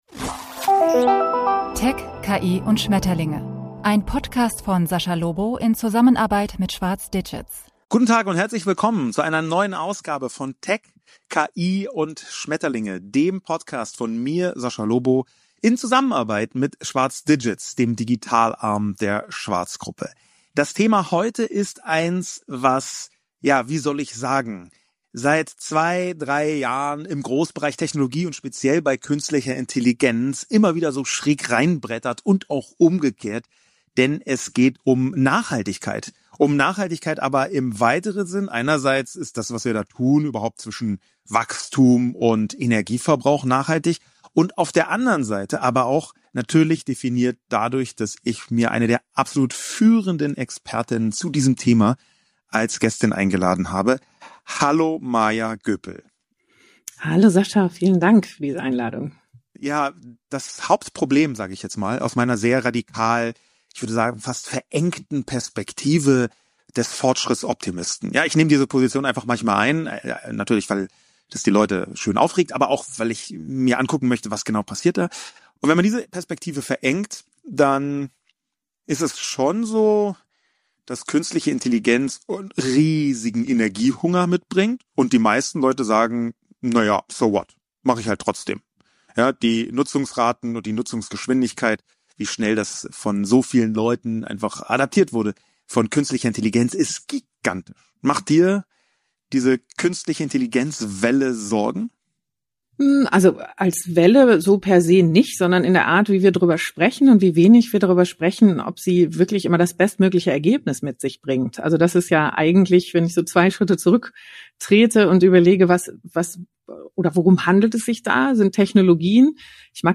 Künstliche Intelligenz gilt als Motor des Fortschritts – doch zu welchem Preis? Sascha Lobo spricht mit Prof. Dr. Maja Göpel - Politökonomin, Transformationsexpertin und Nachhaltigkeitswissenschaftlerin über den Energiehunger von KI, die unsichtbaren Kosten digitaler Technologien und die Frage, wie wir Fortschritt und Nachhaltigkeit zusammenbringen können. Von Smart Grids über Open Source bis Postwachstum – ein Gespräch über Chancen, Risiken und die Werte, die unsere Zukunft prägen werden.